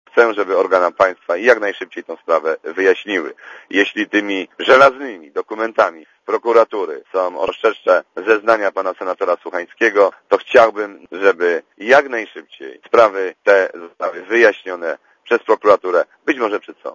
Dla Radia Zet mówi Henryk Długosz (65 KB)